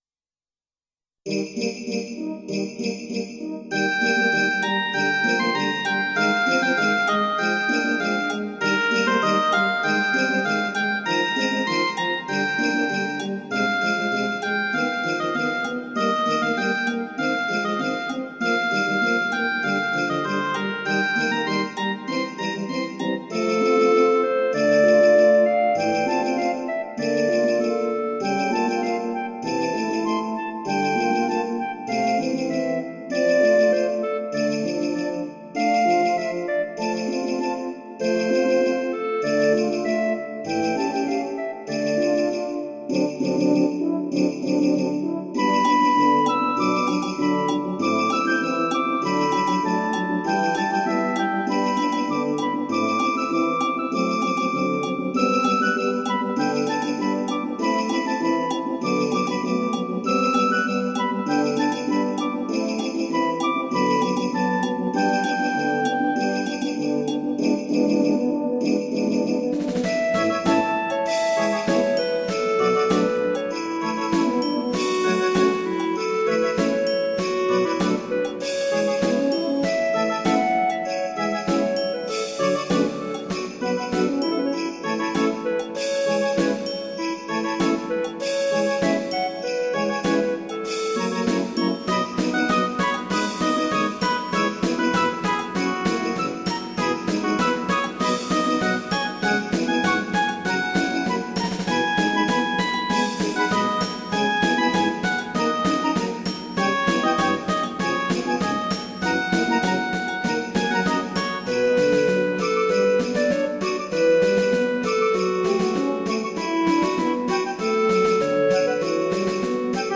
ポップス 　ＭＩＤＩ(34KB） 　YouTube
ＭＰ３(2.4MB） 温暖化のため1度ずつ上昇。